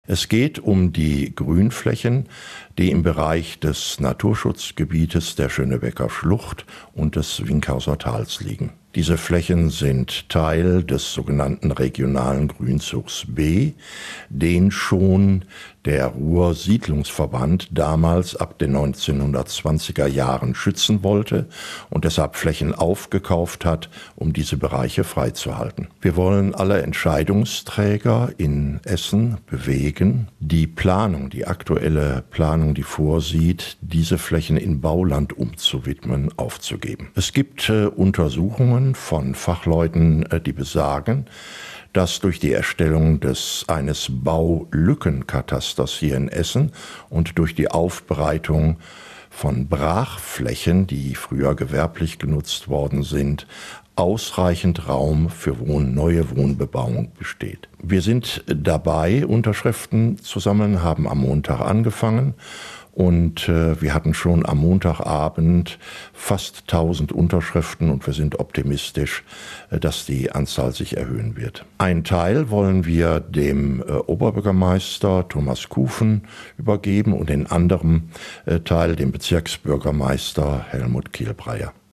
int-bi-schoenebecker-gruenflaechen.mp3